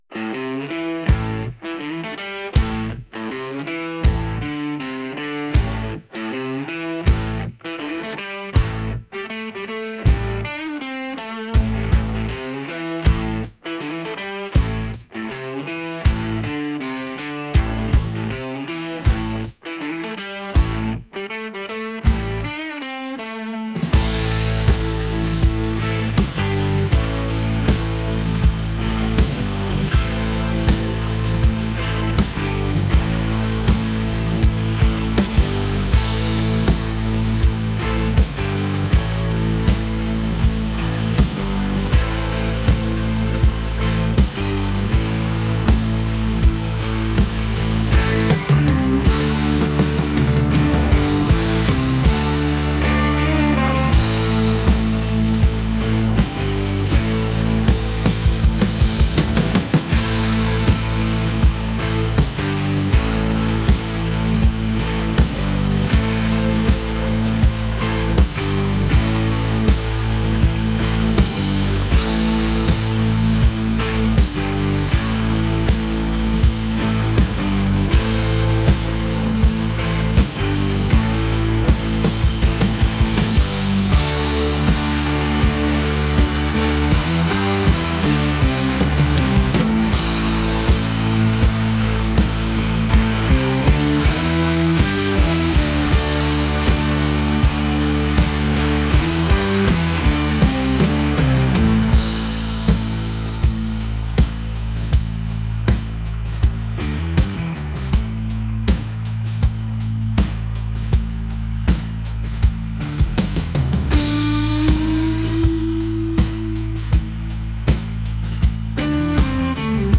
blues | rock